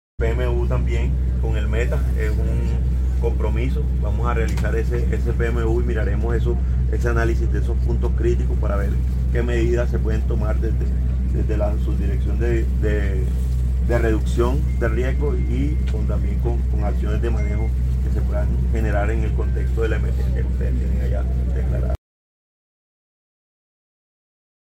Audio: Victor Andrés Mesa Galván, Subdirector UNGRD